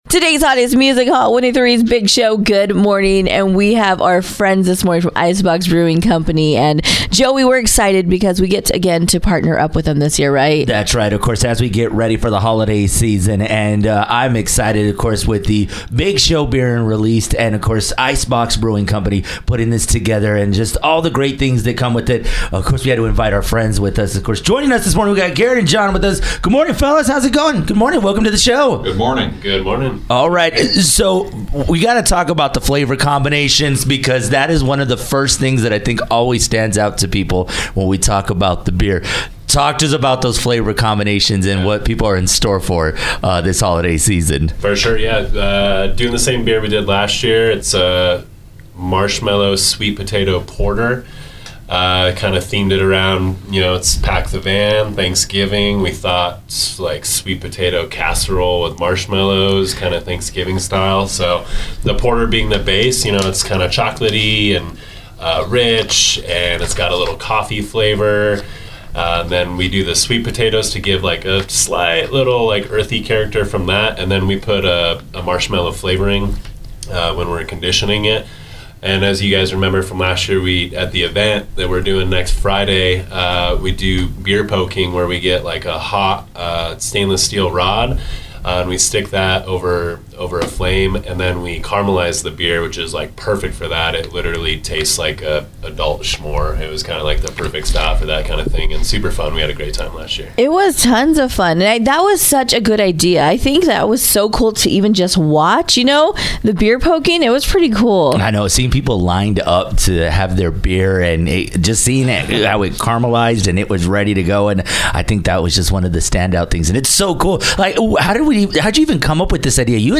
The Big Show Beer interview with Icebox Brewing Company
iceboxinterview.mp3